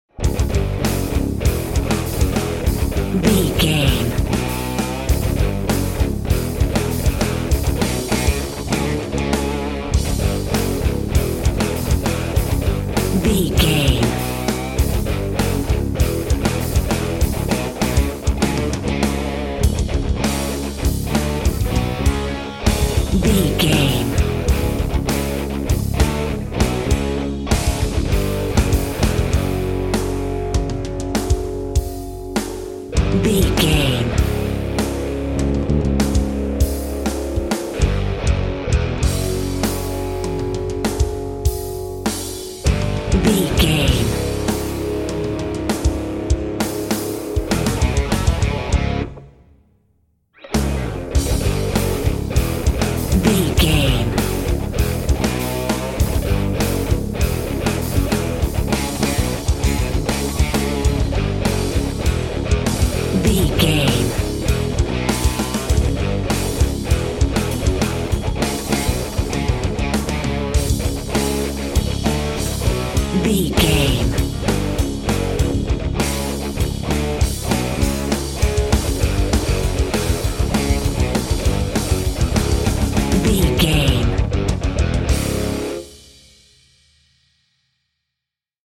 Fast paced
Aeolian/Minor
C♯
drums
bass guitar
electric guitar
medium tempo